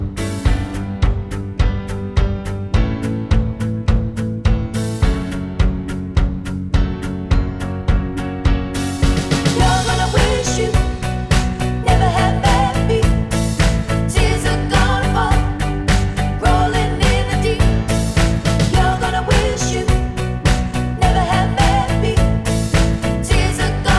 One Semitone Down Pop (2010s) 3:47 Buy £1.50